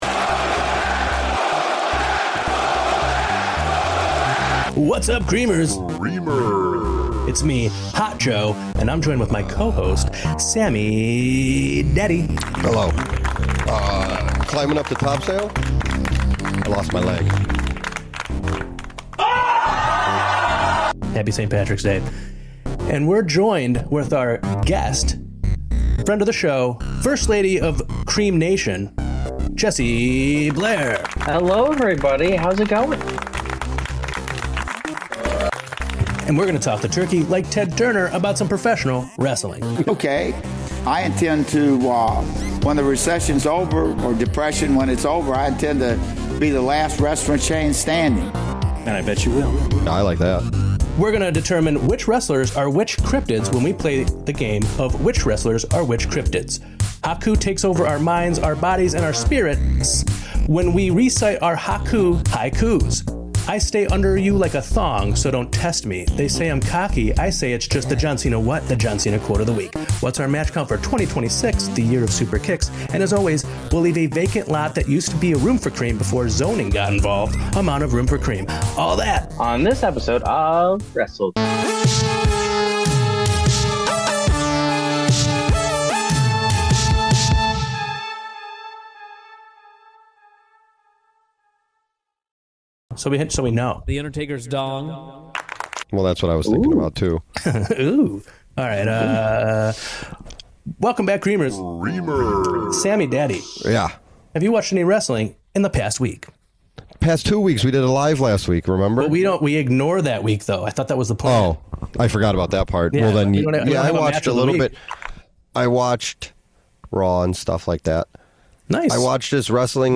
Creamers! on this latest eppy, our beautiful boys are joined by a special guest!